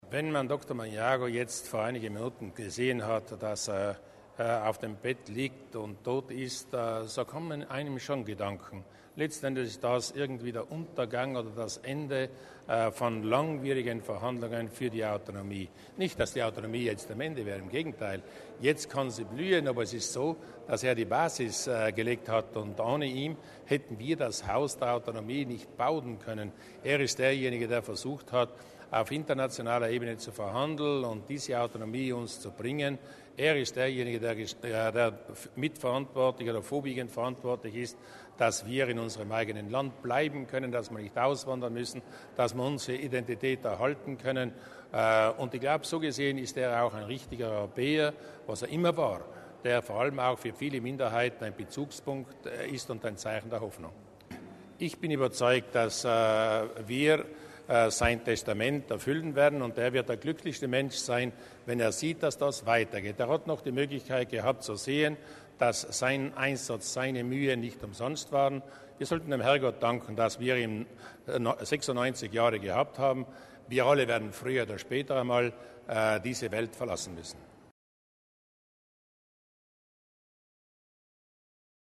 Um ihre Verbundenheit mit dem Verstorbenen, aber auch mit den trauernden Angehörigen zu zeigen, hat die Landesregierung heute die traditionelle Pressekonferenz von Landeshauptmann Luis Durnwalder nach der Sitzung der Landesregierung umfunktioniert, um Altlandeshauptmann Silvius Magnago zu würdigen. Man nehme Abschied von einer außerordentlichen Persönlichkeit, die Großes für Südtirol und die Minderheiten in Europa geleistet habe, so Landeshauptmann Durnwalder, der heute mit der Landesregierung noch einmal die wichtigsten politischen Leistungen Magnagos Revue passieren ließ: von der Wahl zum Vi